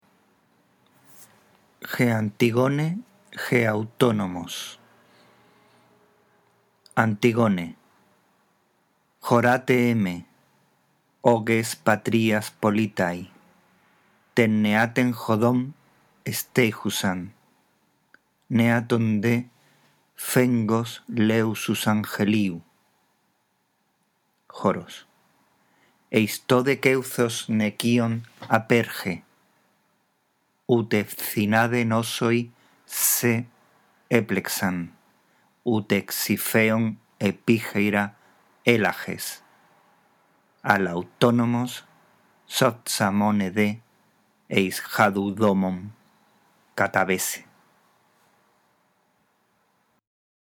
La audición de este archivo te guiará en la lectura del texto griego